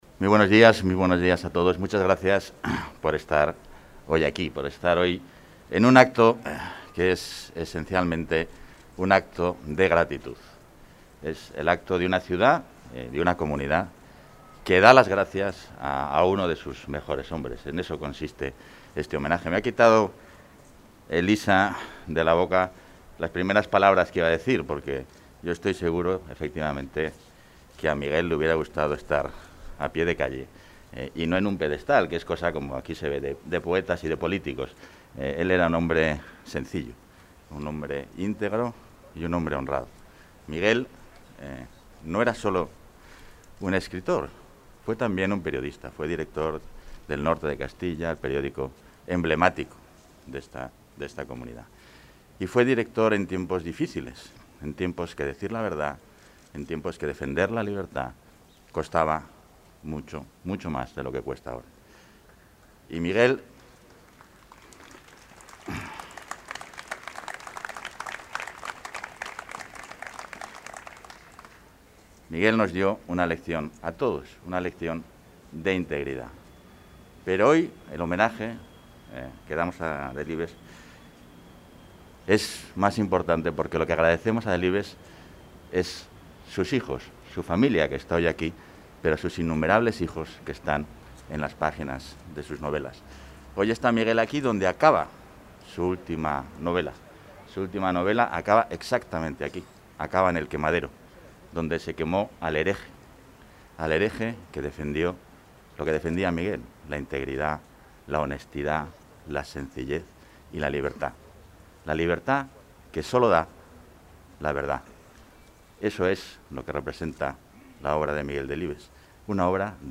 Intervención del vicepresidente de la Junta.
El vicepresidente, portavoz y consejero de Transparencia, Ordenación del Territorio y Acción Exterior, Francisco Igea, ha participado en el acto organizado por la Fundación Miguel Delibes en el que se ha descubierto una escultura del escritor ubicada en el Campo Grande de Valladolid, su localidad natal.